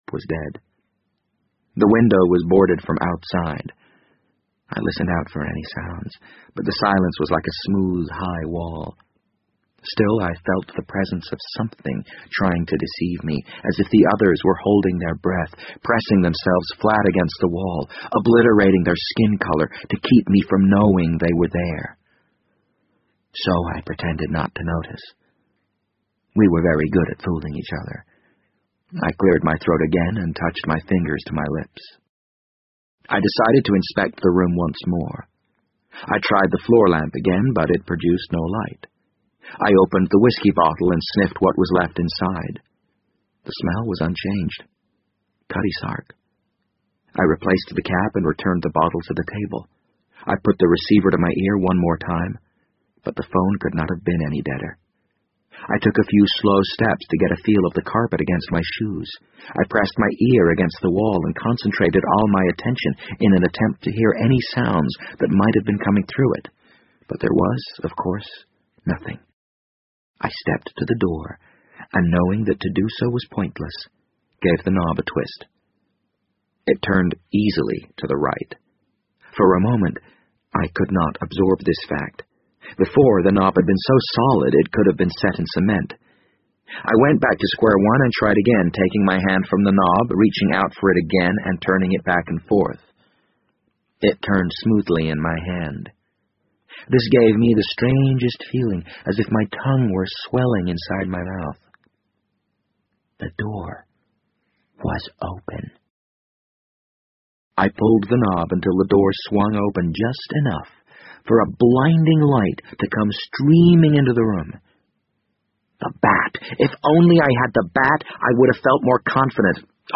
BBC英文广播剧在线听 The Wind Up Bird 014 - 11 听力文件下载—在线英语听力室